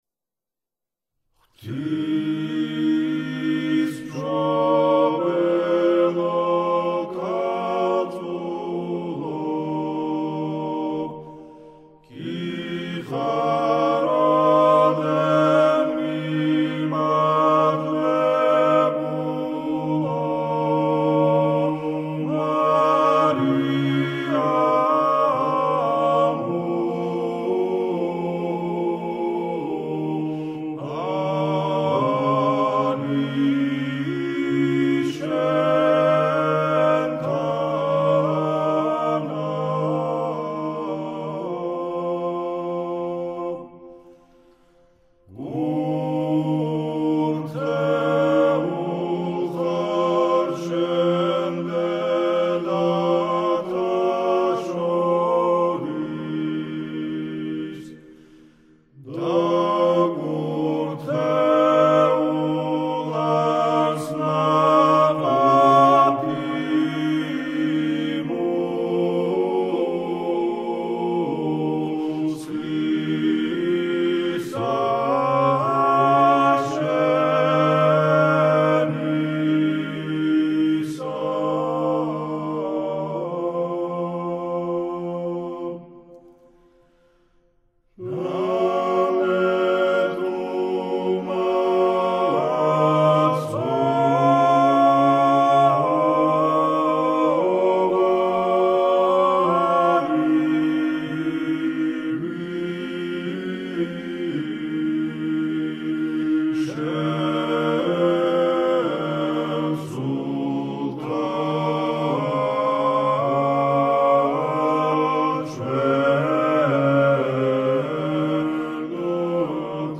საგალობელი: ღვთისმშობელო ქალწულო, ბასიანი, ქართლ-კახური (კარბელაანთ კილო) - საგალობლის ტექსტი
ღვთისმშობელო ქალწულო, ბასიანი, ქართლ-კახური (კარბელაანთ კილო)